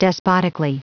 Prononciation du mot despotically en anglais (fichier audio)
Prononciation du mot : despotically